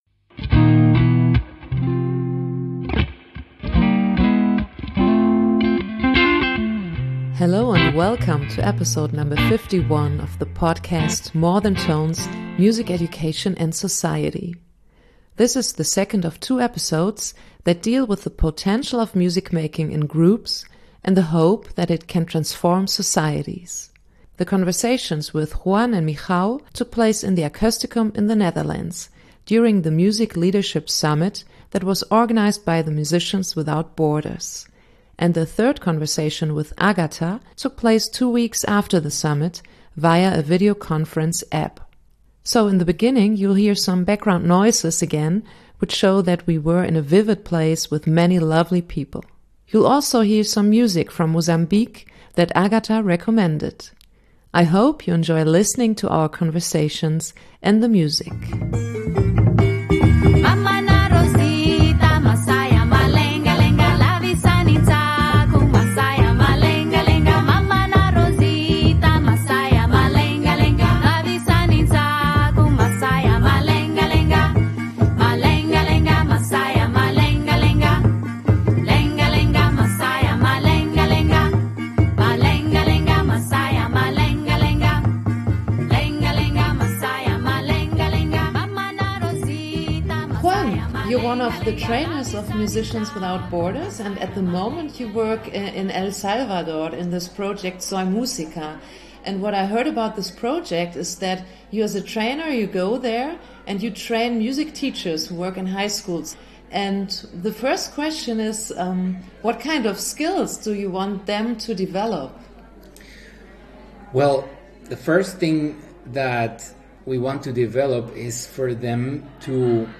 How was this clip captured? This is the second of two episodes that deal with topics I discussed with the organizers, workshop leaders and participants at the “Music Leadership Summit“ which was held by the Musicians Without Borders (MWB).